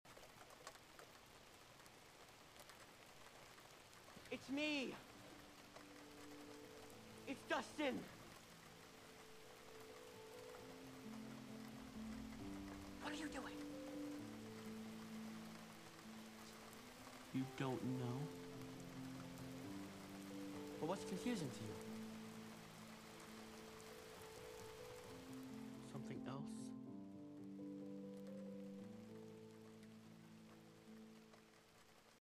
a dustin henderson comfort sound for your listening pleasure